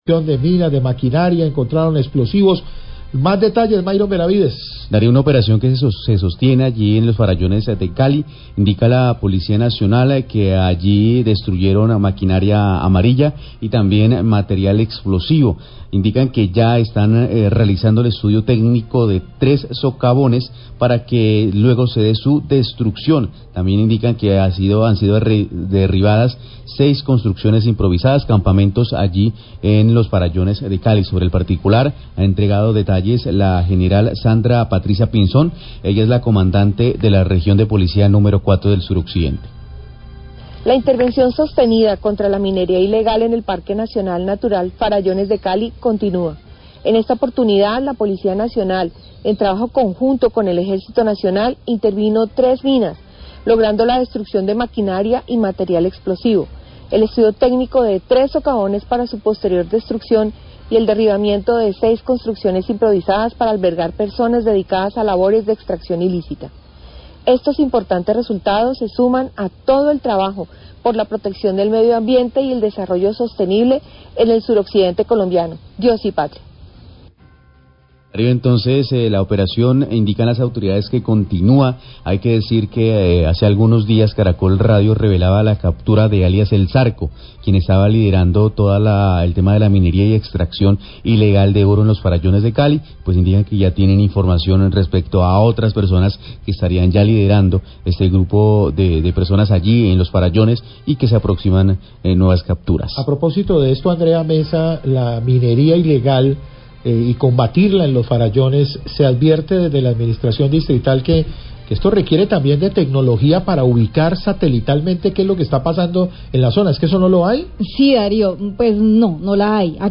Radio
En operación conjunta de la Policía Metropolitana de Cali y el Ejército Nacional, se logró la destrucción de 3 socavones usados para la minería ilegal de oro en el PNN Los Farallones. Declaraciones de la Gral. Sandra Patricia Pinzón, Cdte de la Región de Policía No. 4, y del director del Dagma, Mauricio Mira, quien advierte que en la lucha contra este delito se requiere de vigilancia satelital.